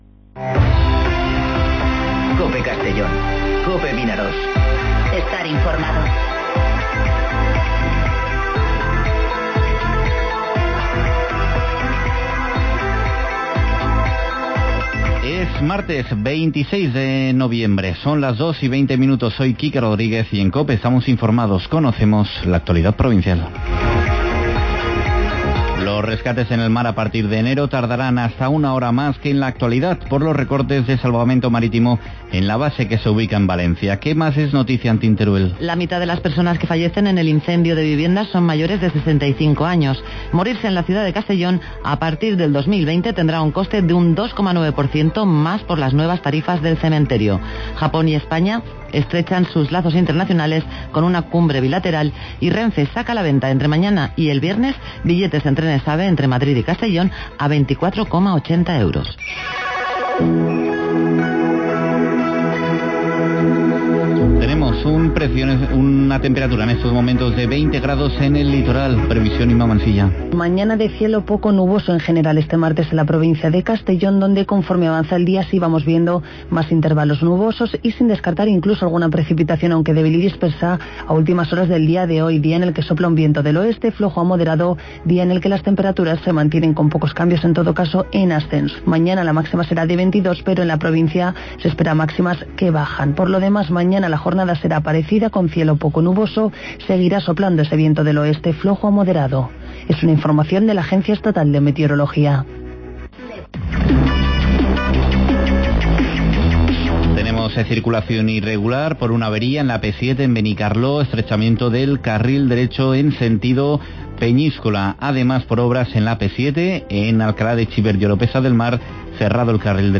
Informativo Mediodía COPE en Castellón (26/11/2019)